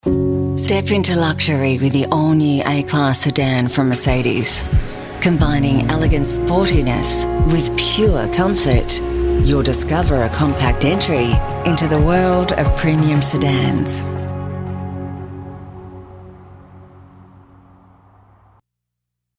Car Spot Confident Voice Over Artists | Voice Fairy